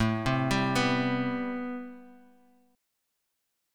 Am9 Chord